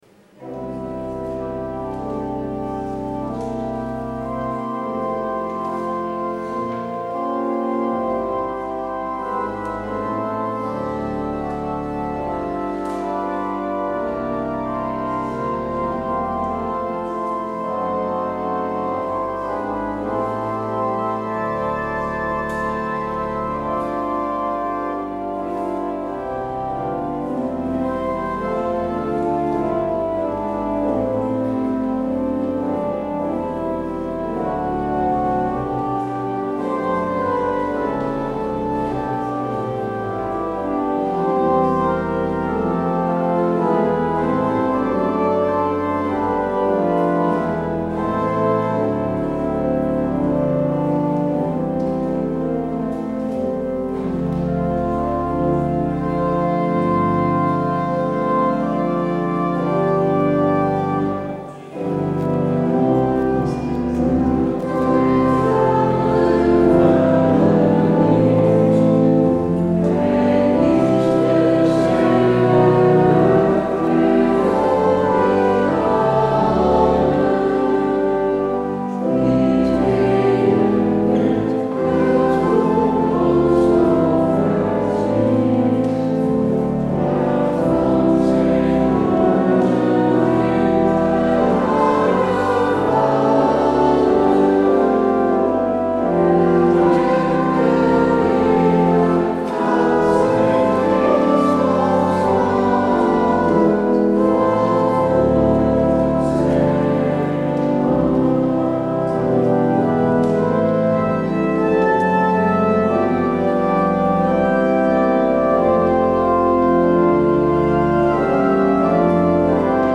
 Luister deze kerkdienst terug